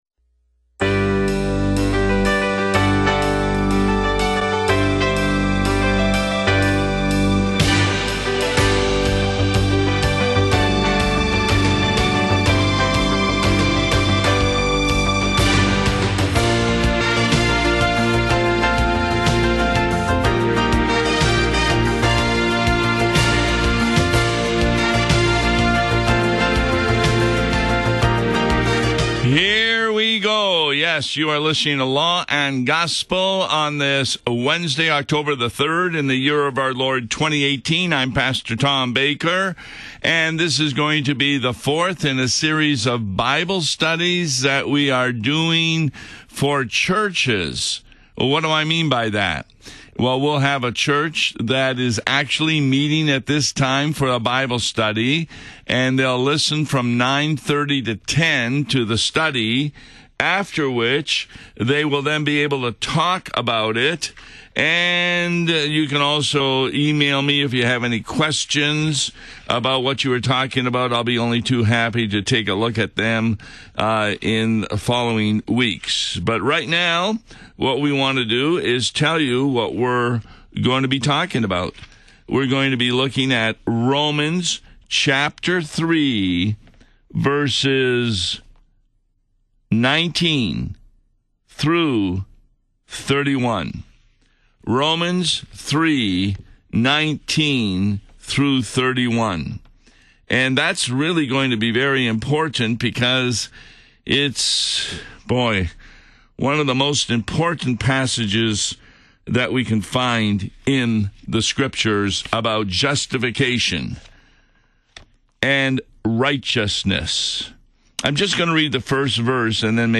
Law and Gospel — Bible Study Wednesday